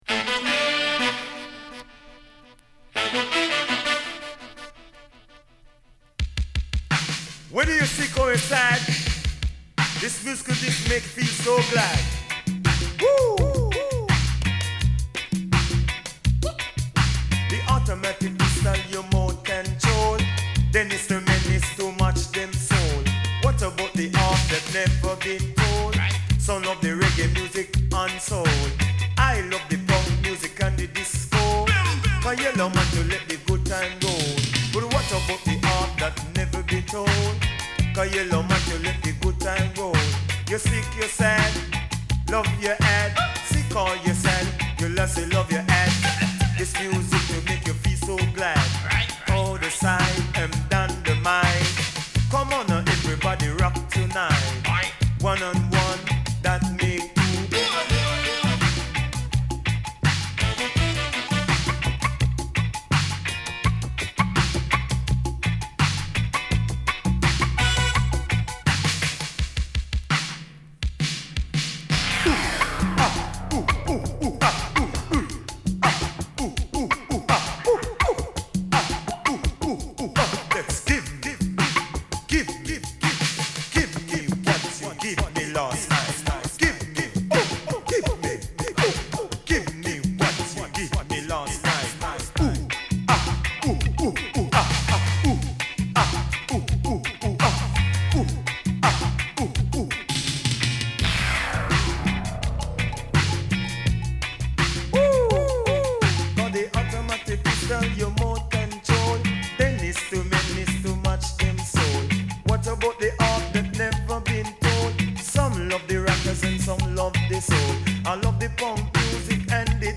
Recorded at Evergreen Studio and RPM Studio
lead vocals
bass guitar
DMX, synthesizer
congas, bells
horns
background vocals.